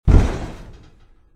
trash can.mp3